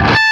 LEAD A 4 LP.wav